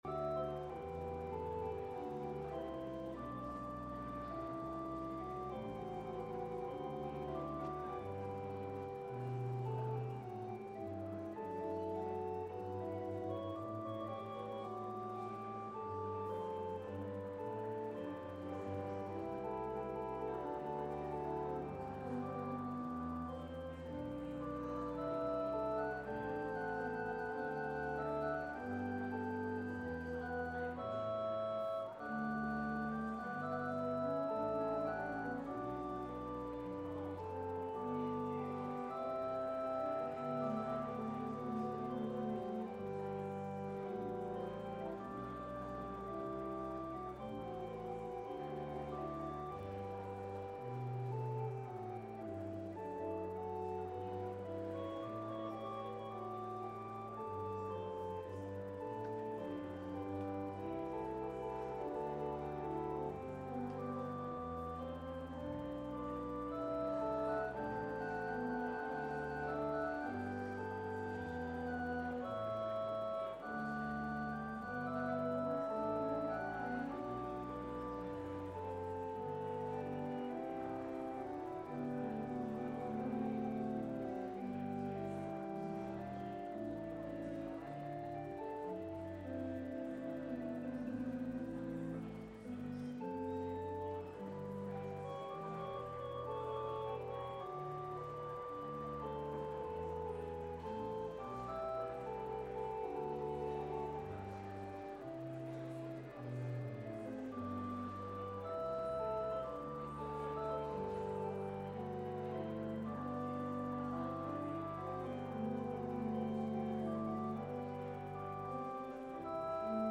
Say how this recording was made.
This service includes the Cantata 61 performed by the Scots’ Church Choir.